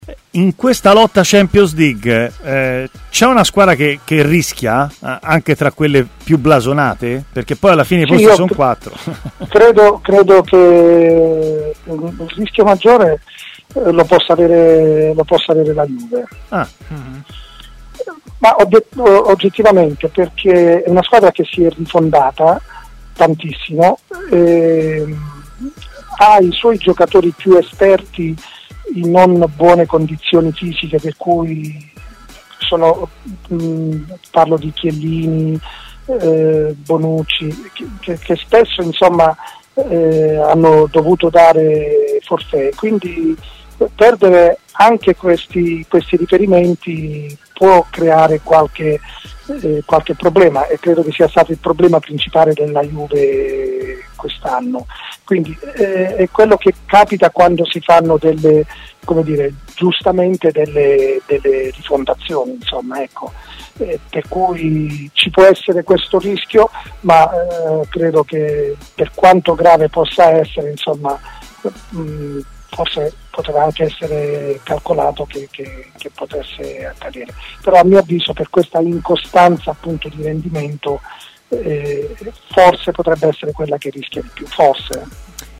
L'allenatore Luigi De Canio ha parlato a TMW Radio, nel corso della trasmissione Stadio Aperto